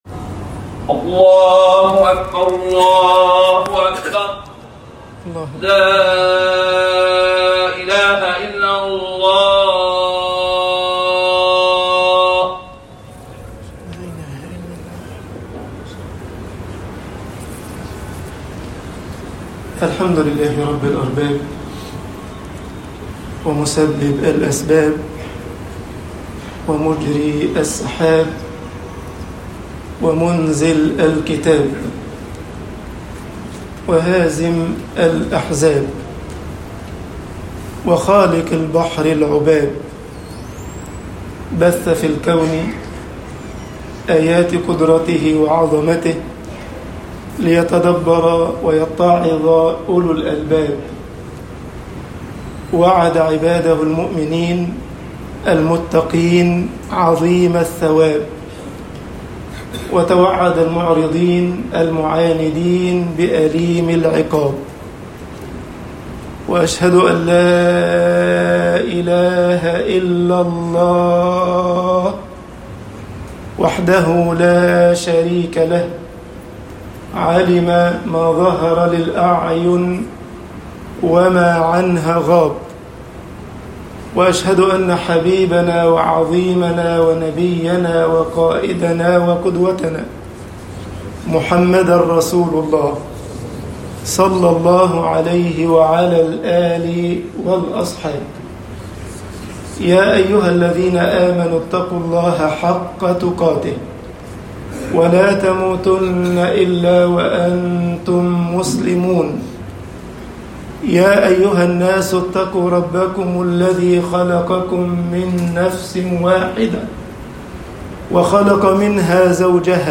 خطب الجمعة - مصر الخير والشر يتصارعان طباعة البريد الإلكتروني التفاصيل كتب بواسطة